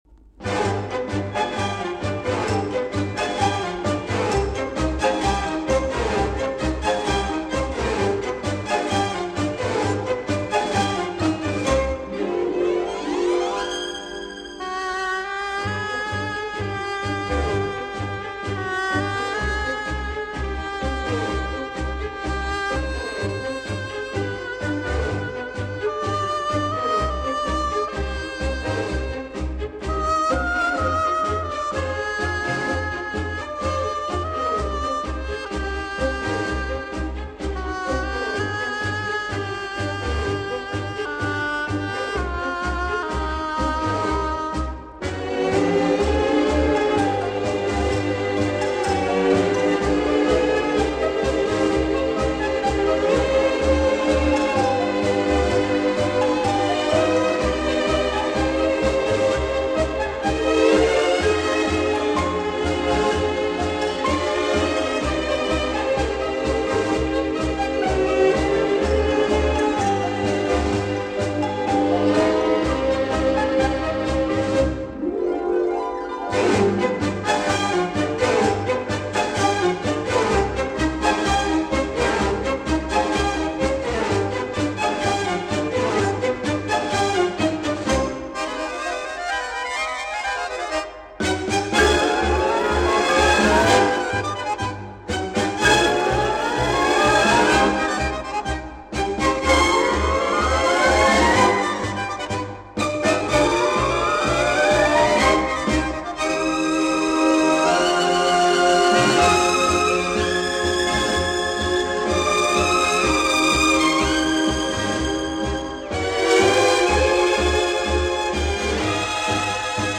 的擅长，优美动听的探戈节奏，使舞者动情和陶醉。